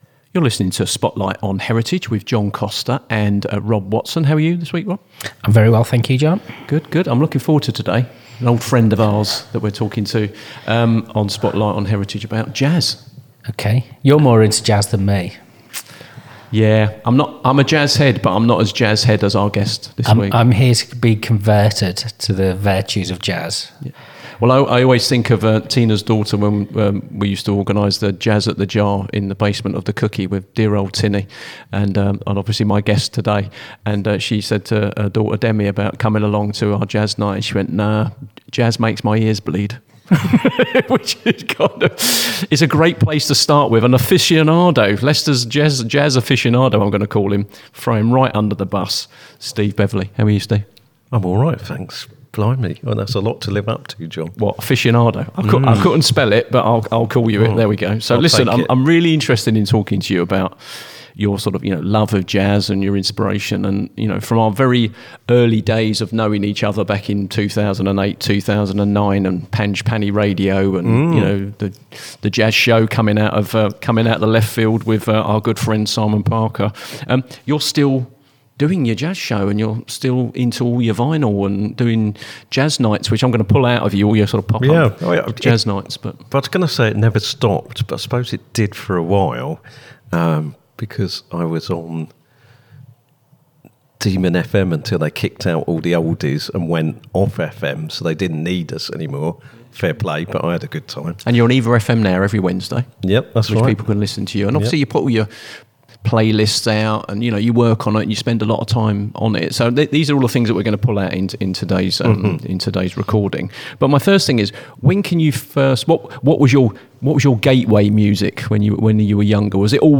reflective conversation about music, memory, and the role jazz has played in shaping both personal identity and local culture